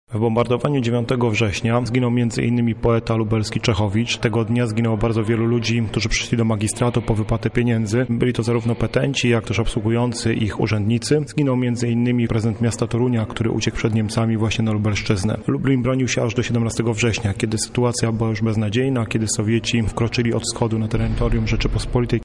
historyk